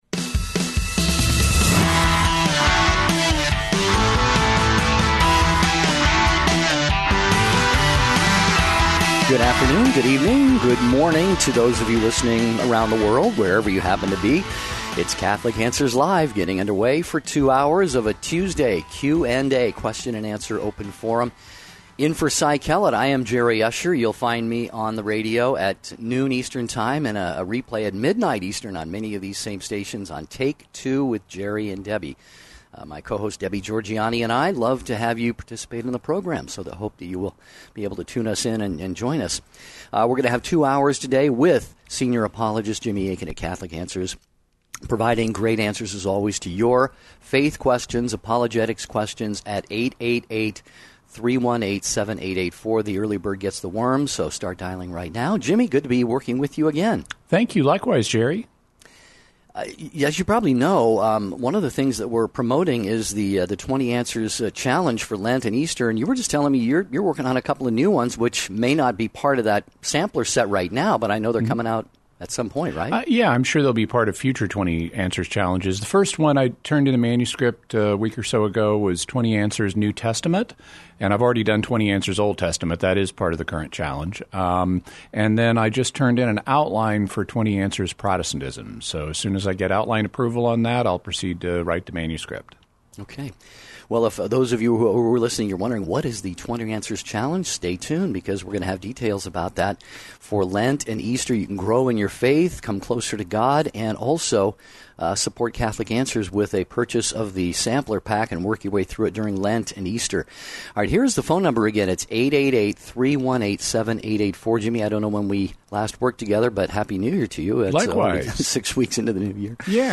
Open Forum